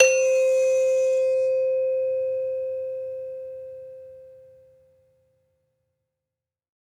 Saron-2-C4-f.wav